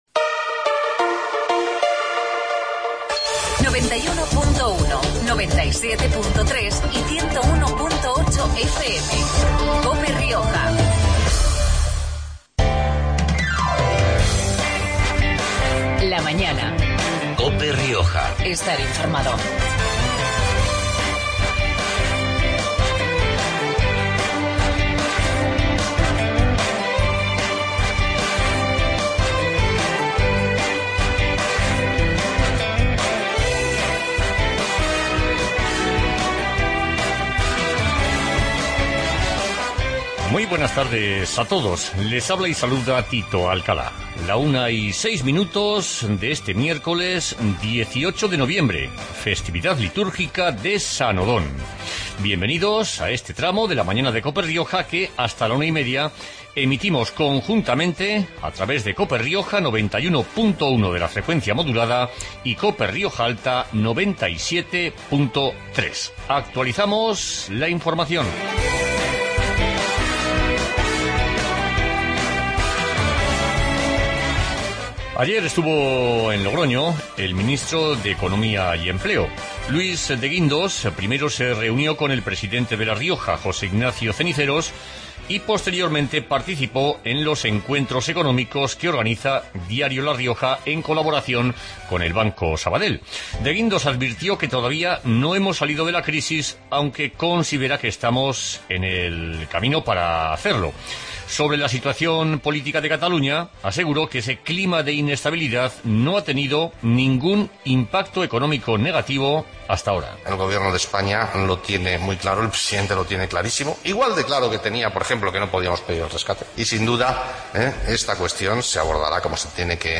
Magazine de actualidad riojana